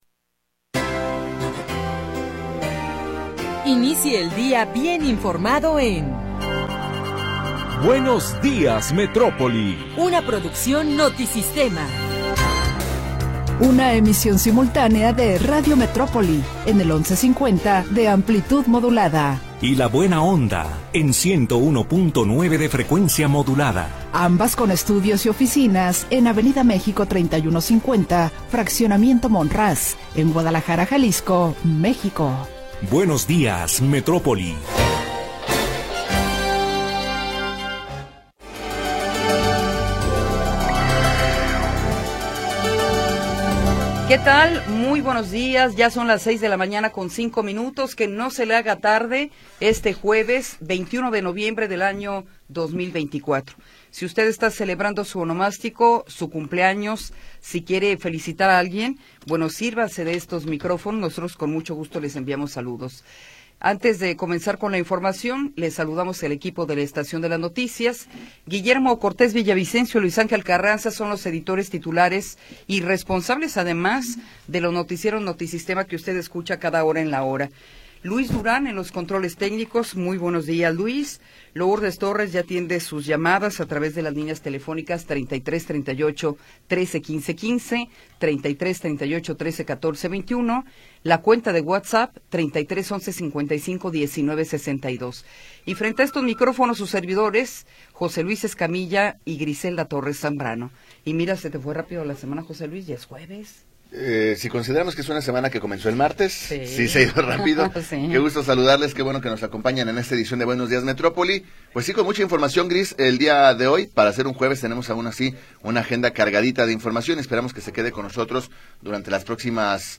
1 Metrópoli al Día 2da Hora - 20 de Noviembre de 2024 44:51 Play Pause 13h ago 44:51 Play Pause Lire Plus Tard Lire Plus Tard Des listes J'aime Aimé 44:51 La historia de las últimas horas y la información del momento. Análisis, comentarios y entrevistas